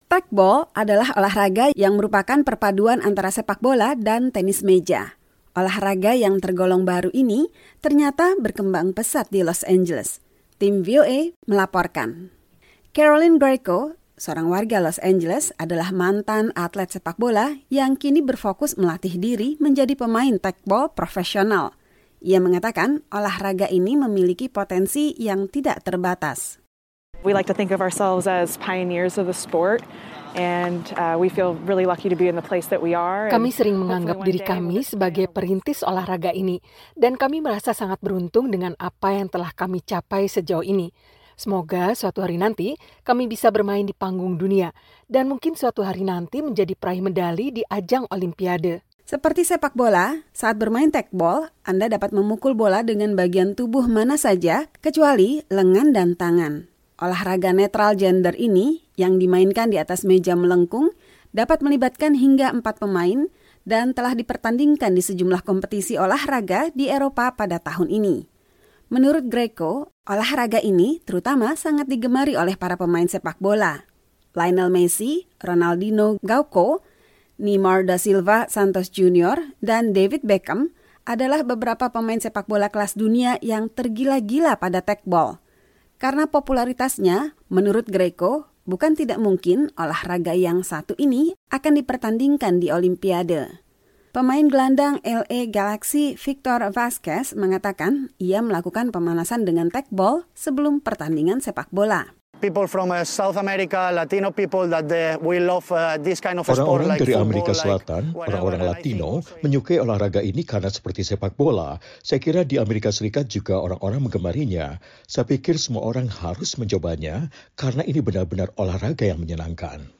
Tim VOA melaporkan.